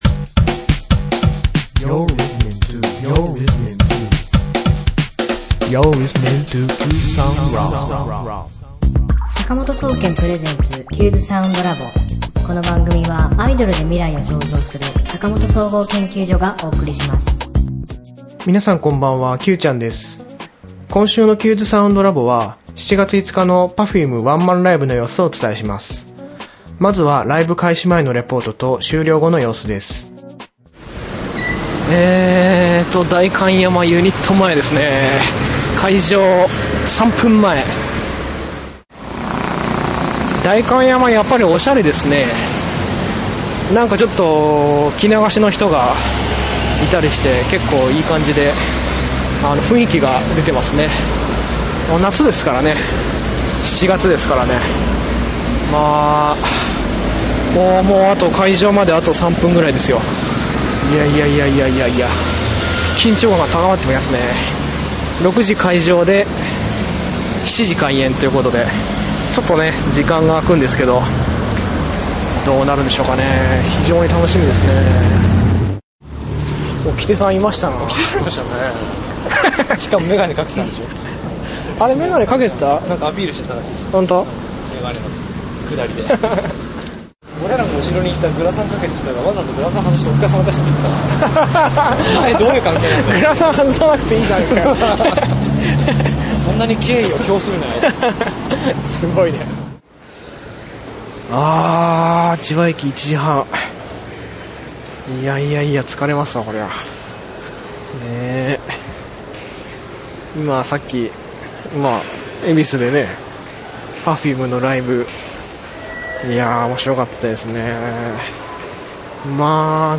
興奮のレポートをお聴きください。